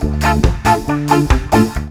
SPEEDLOOP1-L.wav